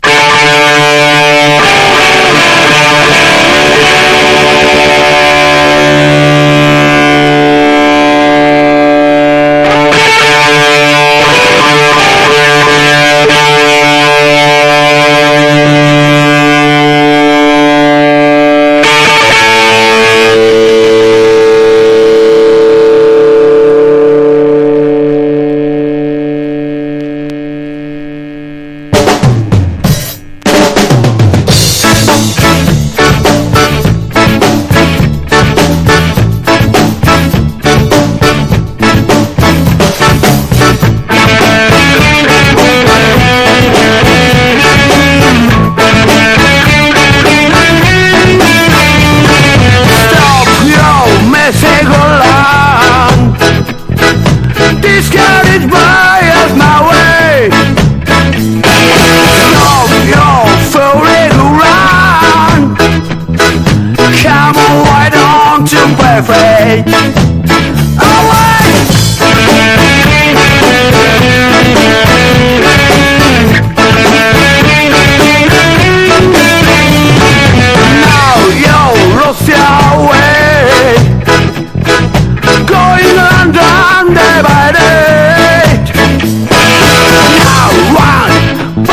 PUNK / HARDCORE# REGGAE / SKA / DUB# 90-20’S ROCK